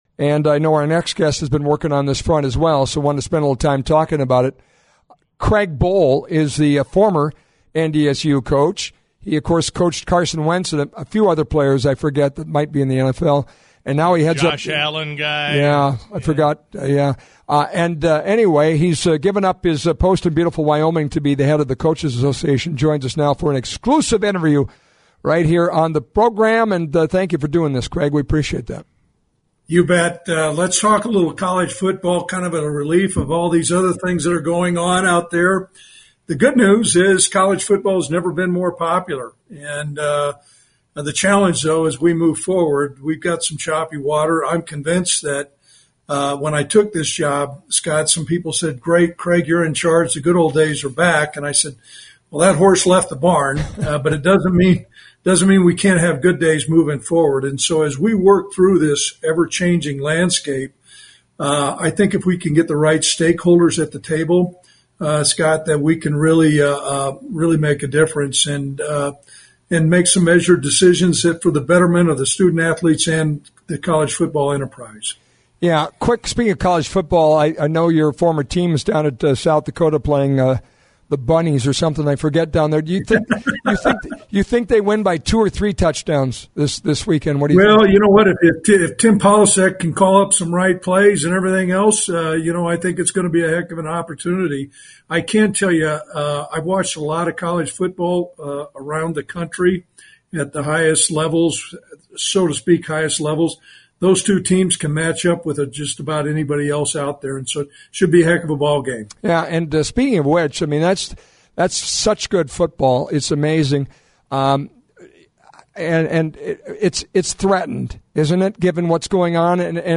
AFCA Executive Director Craig Bohl on The Flag’s What’s On Your Mind